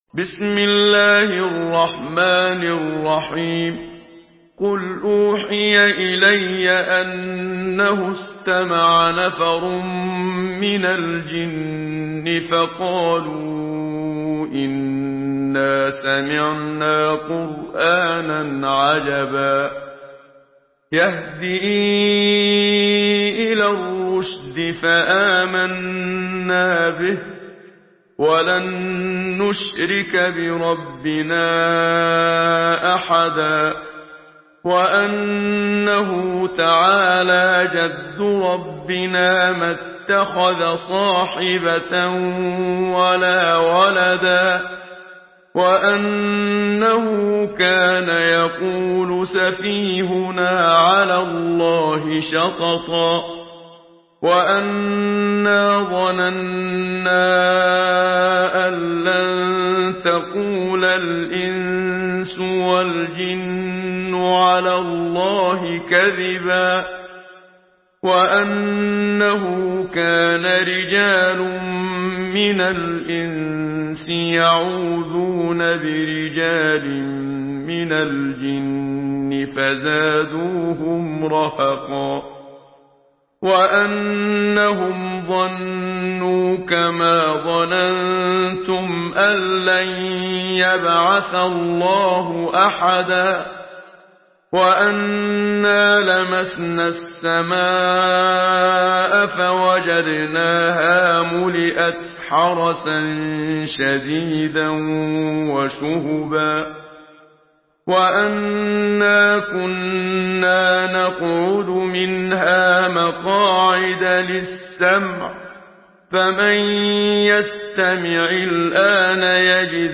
Une récitation touchante et belle des versets coraniques par la narration Hafs An Asim.
Murattal